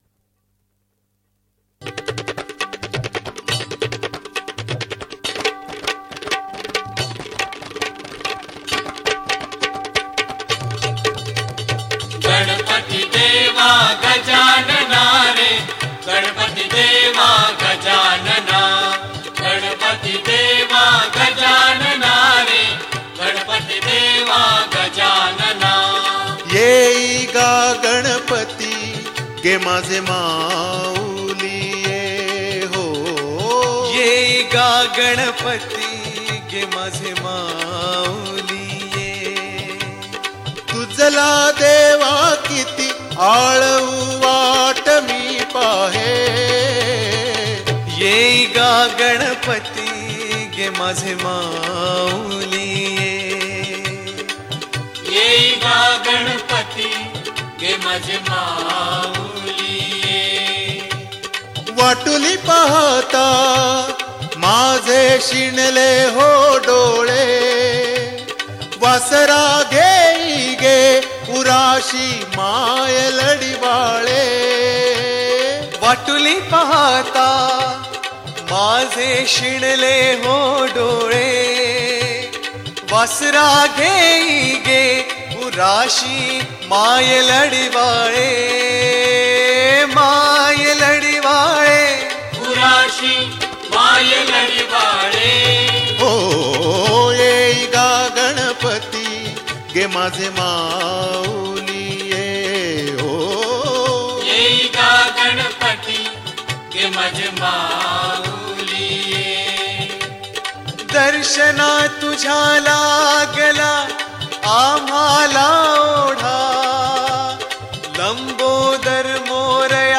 Indipop
Ganesh Chaturthi Special Mp3 Songs